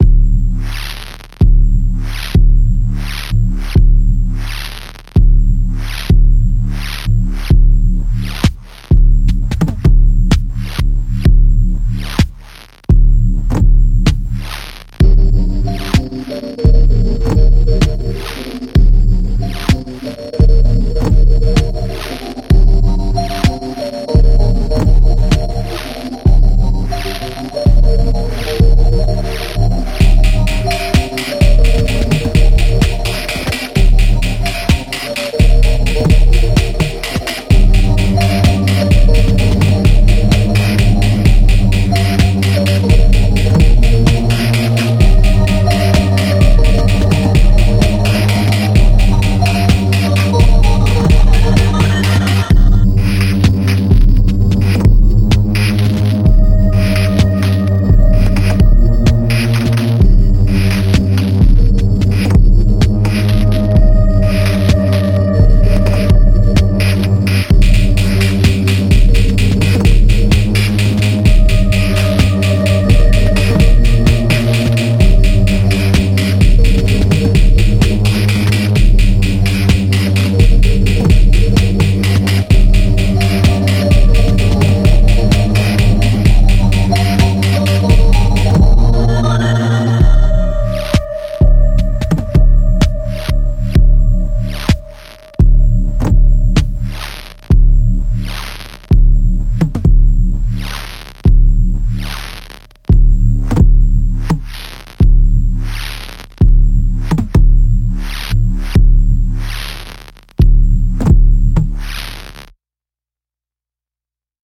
Light and dark, analog and digital, real and surreal.